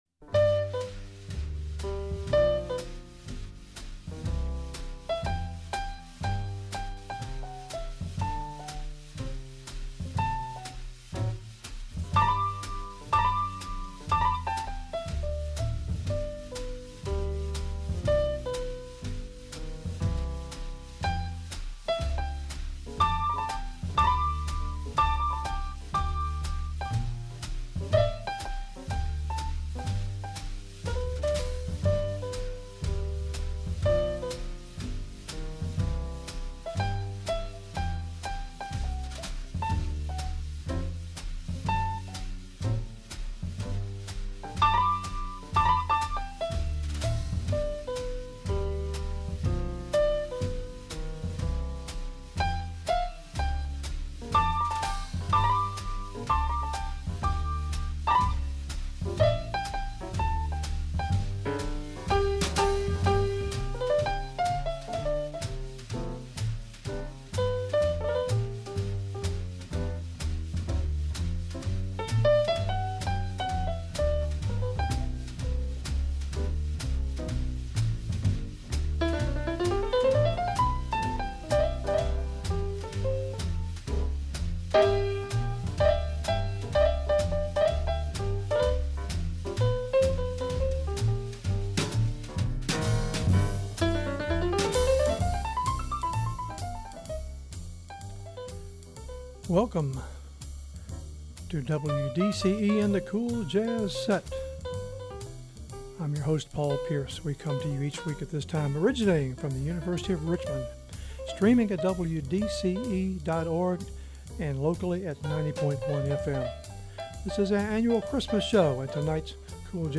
Swinging into Christmas; 12/20/15; Set 1 Subtitle: cool jazz set Program Type: Unspecified Speakers: Version: 1 Version Description: Version Length: 1 a.m. Date Recorded: Dec. 20, 2015 1: 1 a.m. - 52MB download